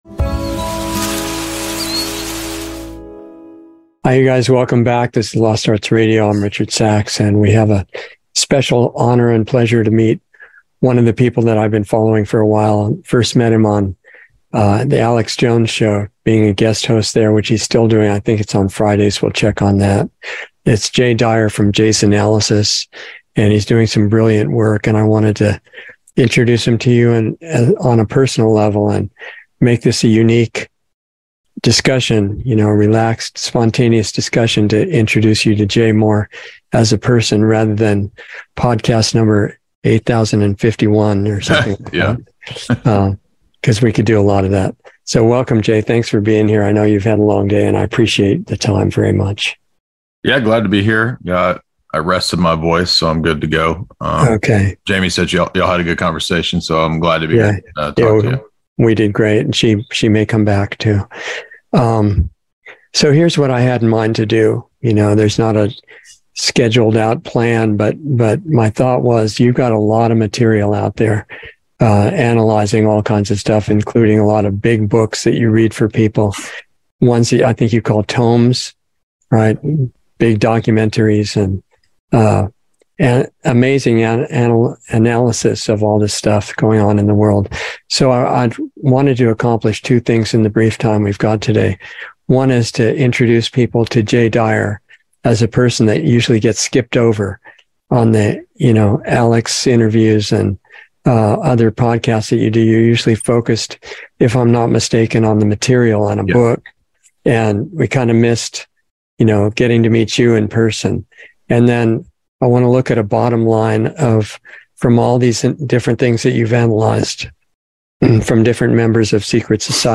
Lost Arts Radio Show on Sunday 6/4/23